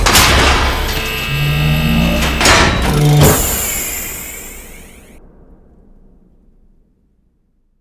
openairlock.wav